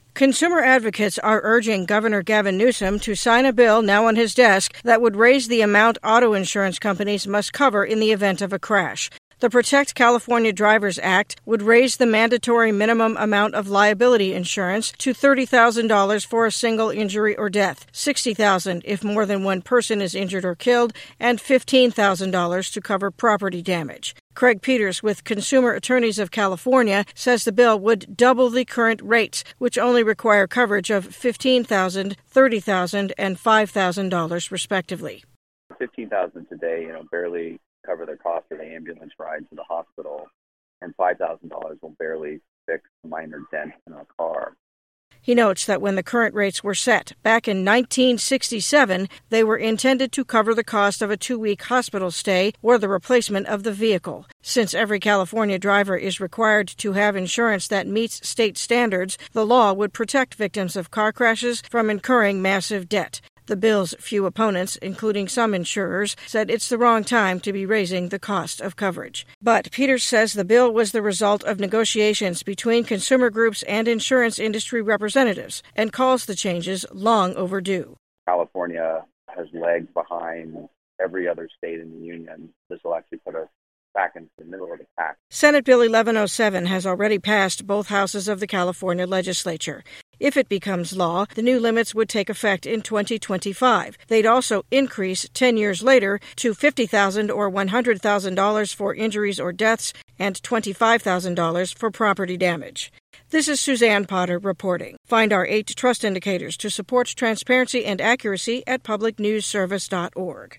Audio Story from California News Service on raising minimum amount auto insurance must cover in CA.
knowledgeable, authoritative on-air presence.